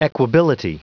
Prononciation du mot equability en anglais (fichier audio)
Prononciation du mot : equability